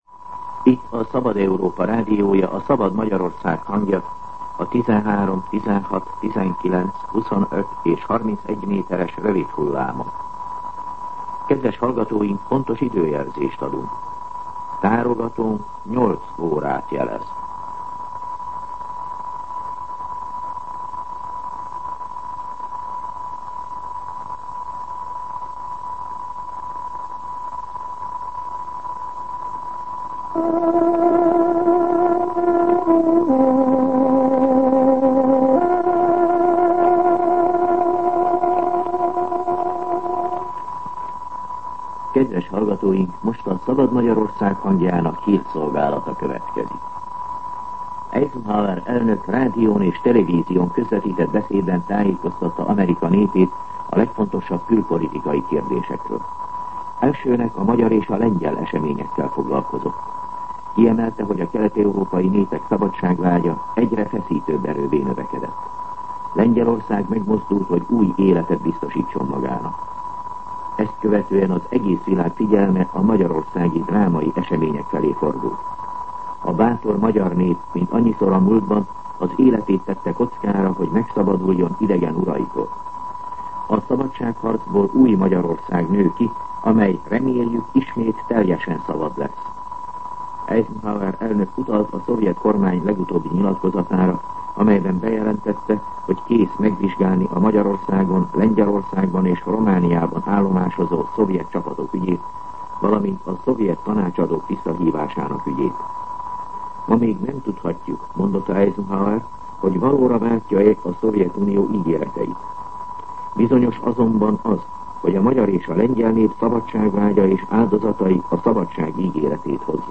08:00 óra. Hírszolgálat